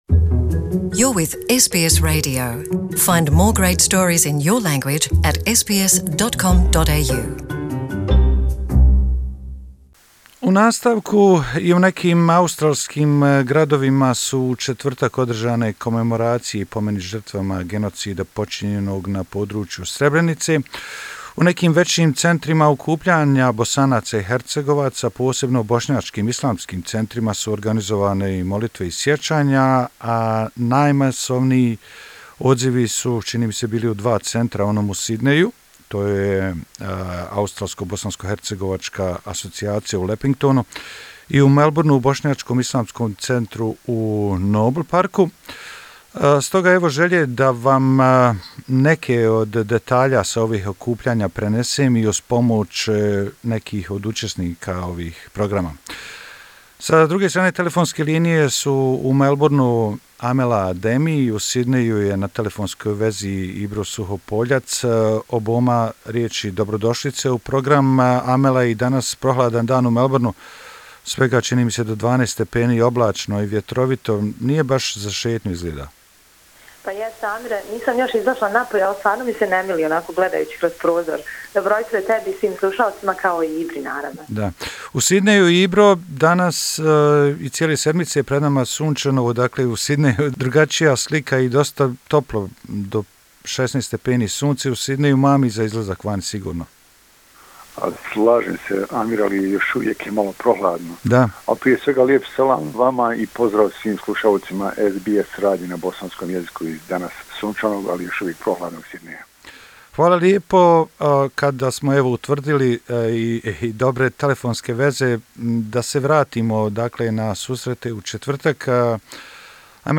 su nam govorili ispred učesnika i organizatora programa